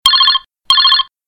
Мемы категории "Уведомления"